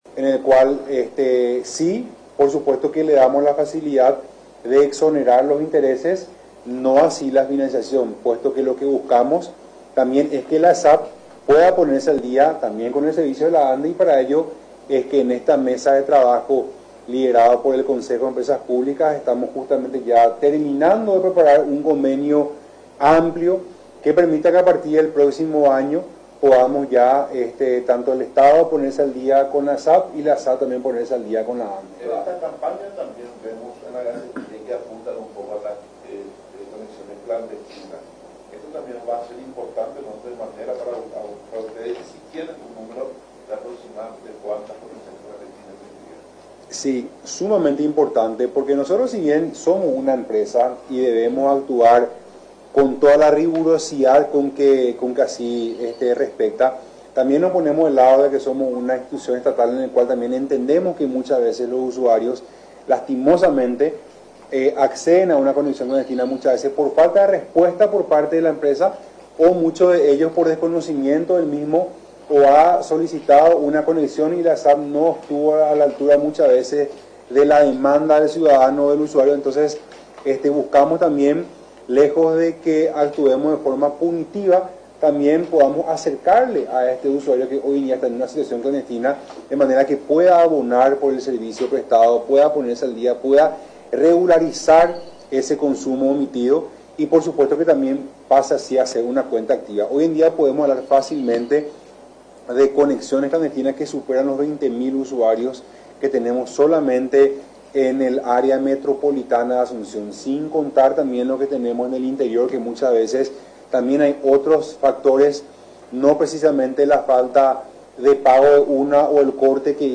Recordó, durante la conferencia de prensa, realizada en la sede de la aguatera estatal, que cuentan unos 60.000 usuarios de 350.000 usuarios en general que se tiene a nivel nacional, entre cuentas activas, cortadas y algunas suspendidas.